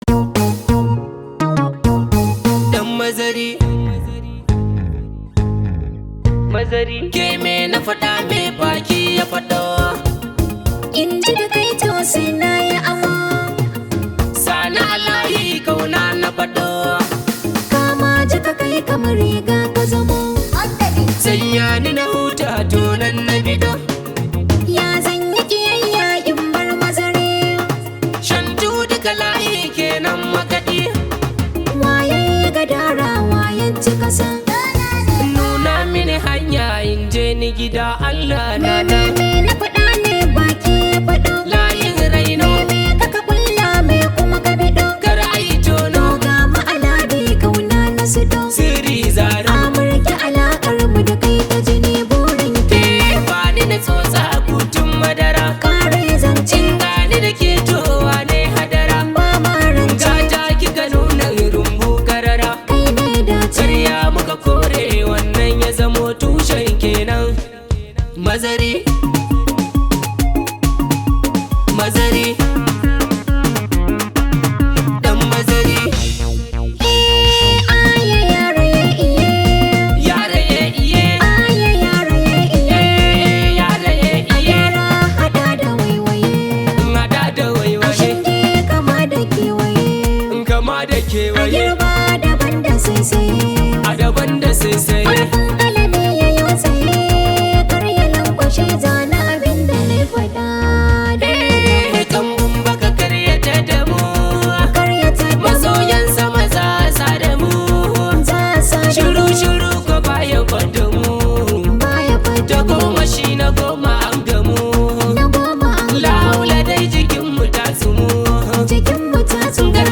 hausa music track
an Arewa rooted song